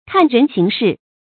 看人行事 kàn rén xíng shì
看人行事发音